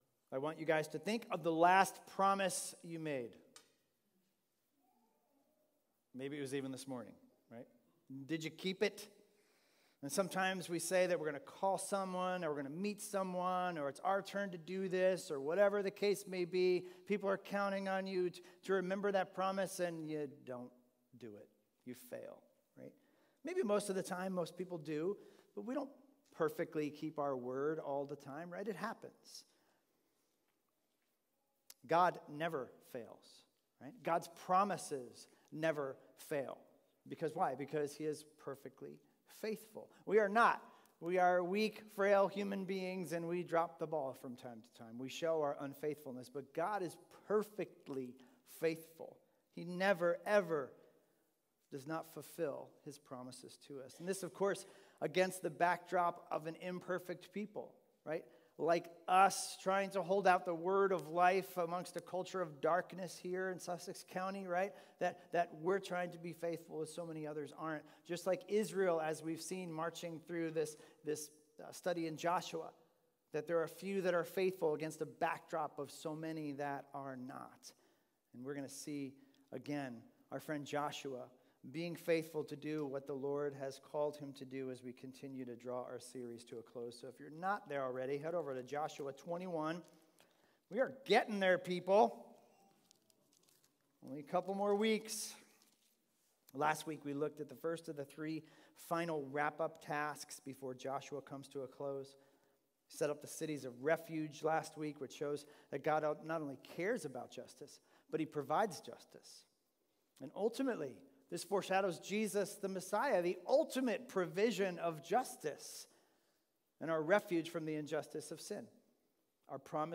Sermons – Highlands Bible Church
Expositional teaching series thru the book of Joshua.